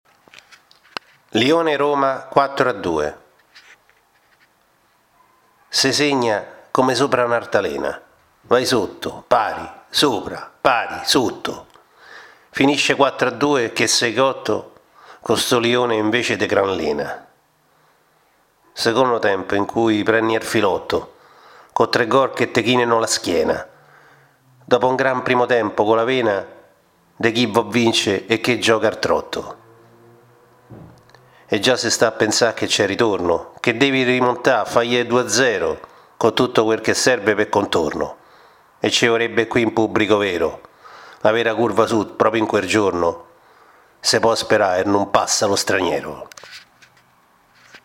L’audiosonetto: